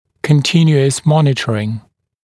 [kən’tɪnjuəs ‘mɔnɪtərɪŋ][кэн’тинйуэс ‘монитэрин]постоянное наблюдение